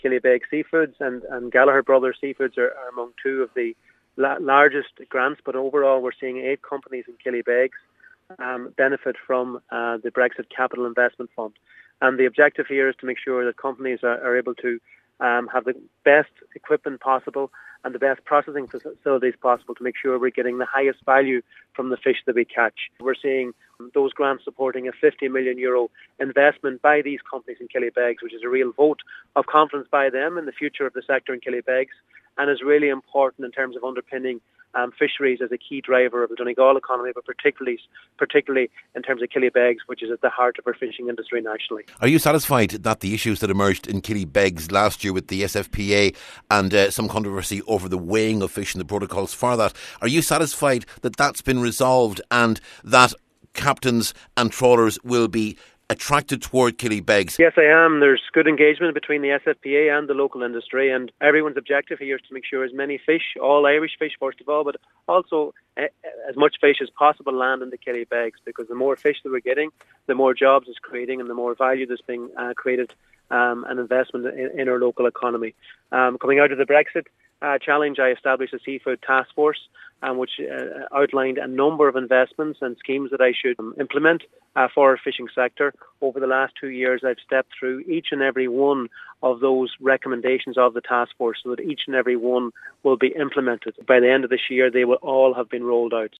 He was speaking after the funding was confirmed yesterday by BIM.